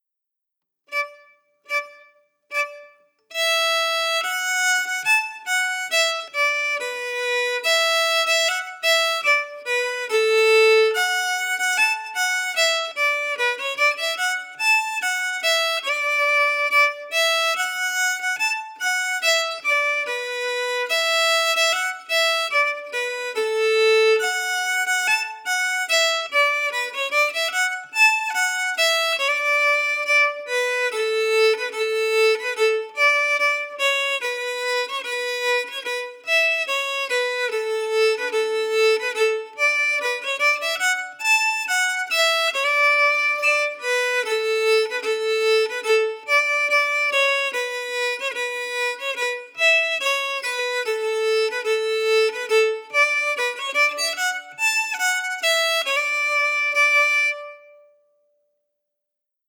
Key: D
Form: March or reel
Played slowly for learning